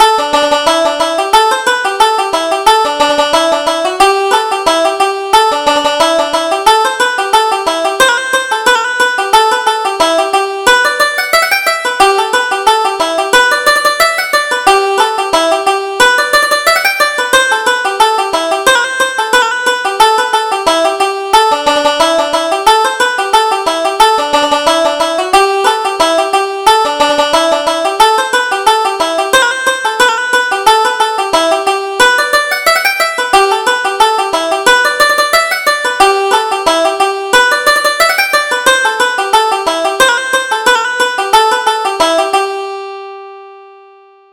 Reel: The Threepenny Bit